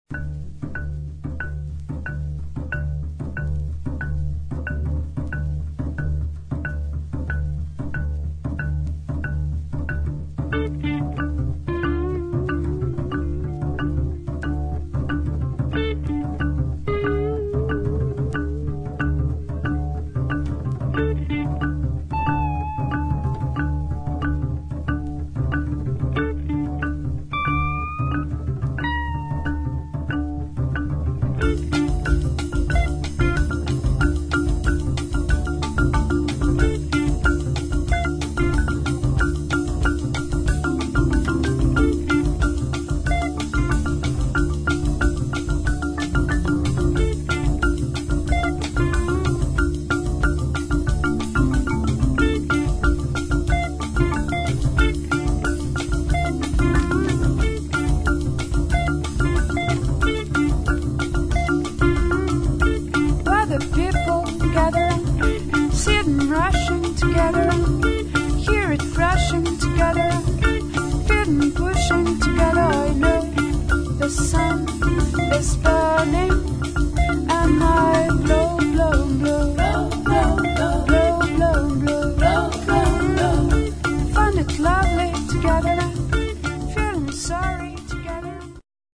[ JAZZ | ROCK | FUNK | WORLD ]